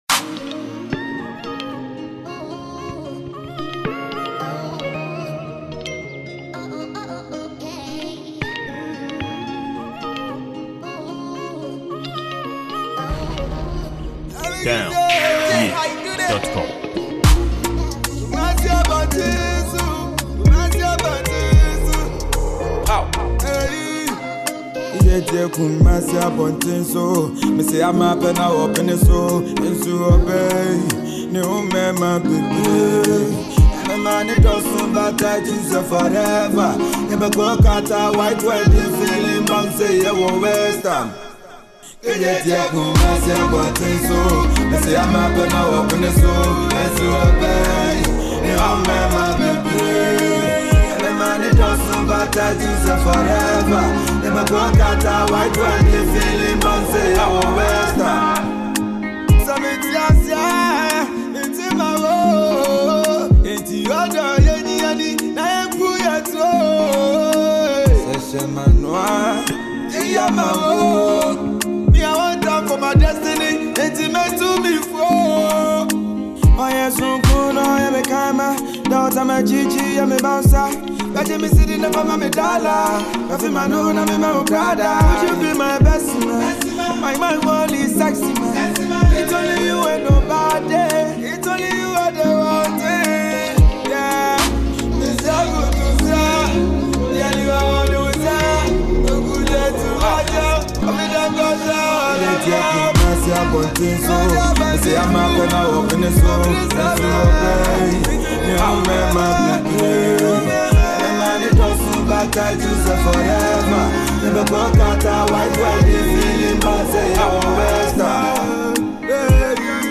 a fresh afrobeat hit from Ghana for your playlist.
Genre: Hiplife